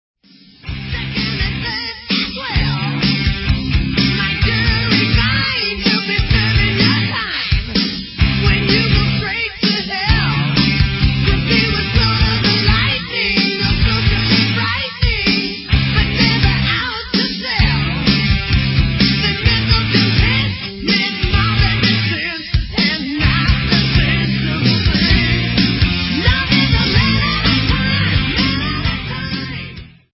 GUITAR
DRUMS
VOCALS
BASS